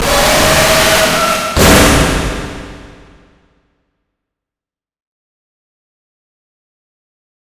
Fnaf Jumpscare But (update) Bouton sonore